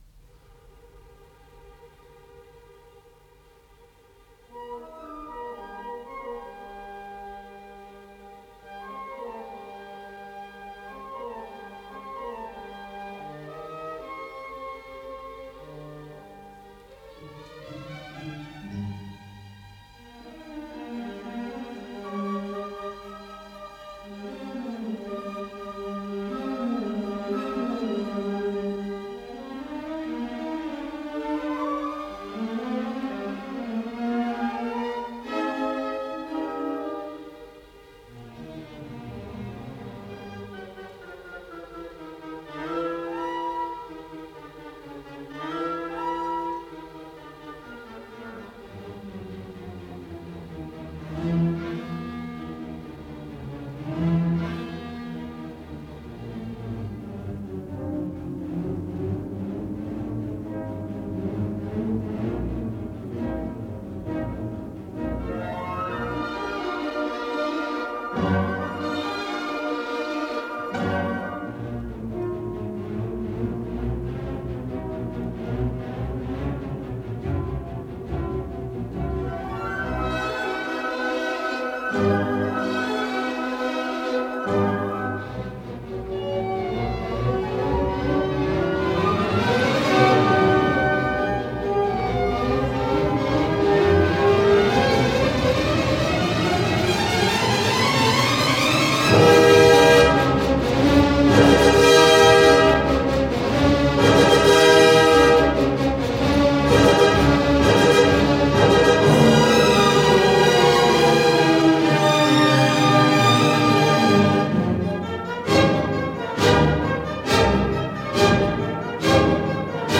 Исполнитель: Государственный симфонический оркестр СССР
Соль минор.